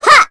Sonia-Vox_Attack2.wav